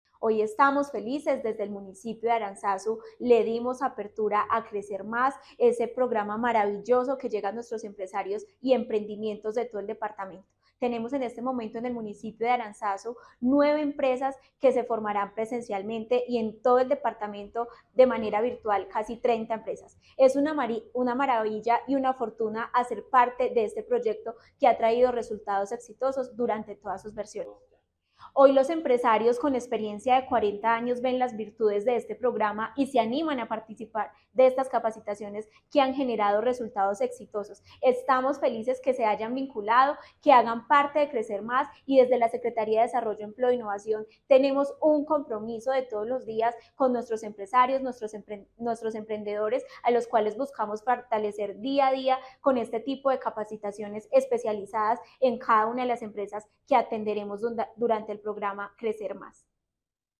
Tania Echeverry Rivera – Secretaria de Desarrollo, Empleo e Innovación de Caldas.